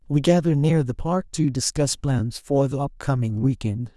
PromptTTS++/sample2/Template2/Condition/Friends/Age/child.wav
child.wav